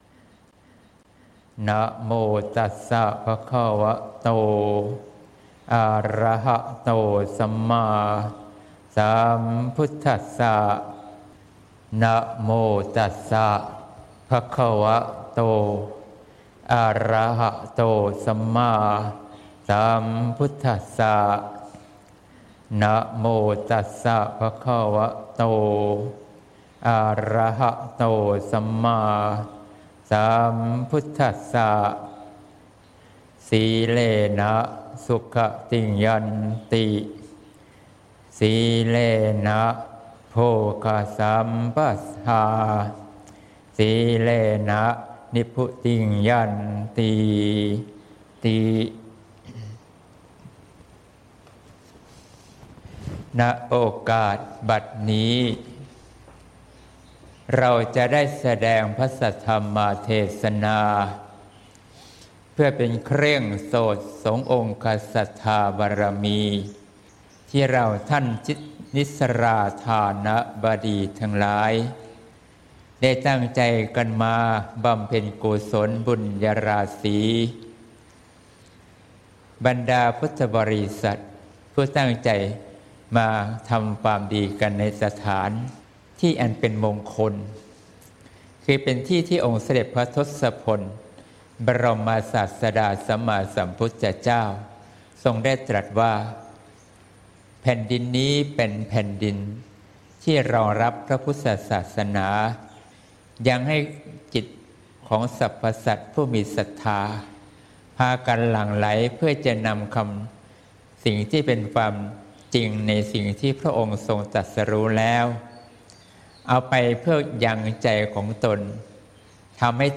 เทศน์ (เสียงธรรม ๑๐ ส.ค. ๖๘)